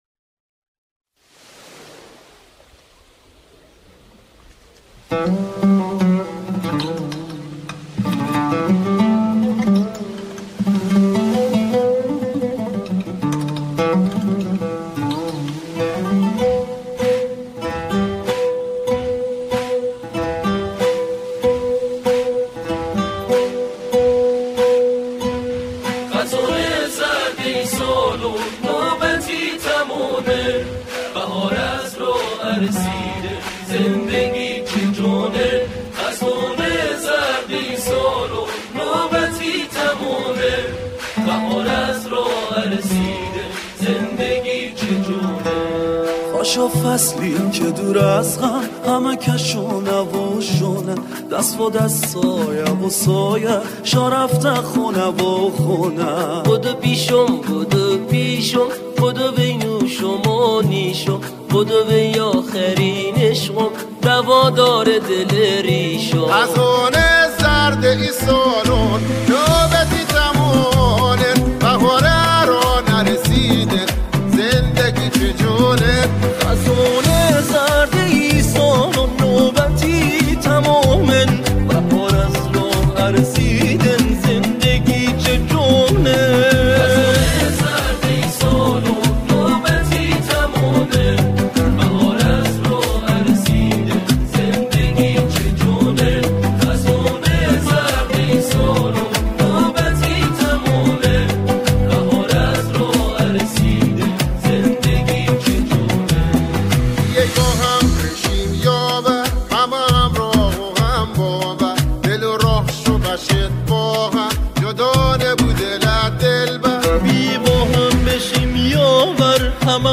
گروه کر